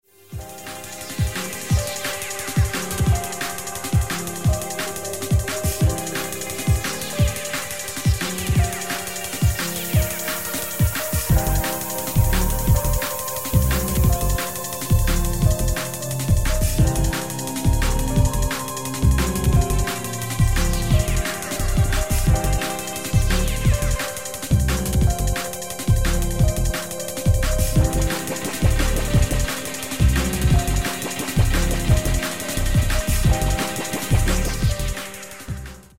Through the vehicle of DJ-led, electronic worship music
DJ led worship
full of drum beats, bass, spoken Word, and layered vocals